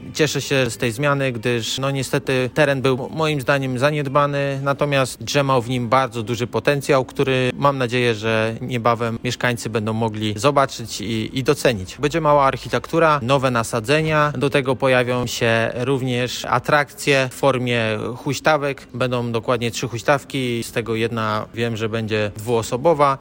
Pojawią się także huśtawki – mówi Rafał Kasza, zielonogórski radny, jeden z inicjatorów skweru: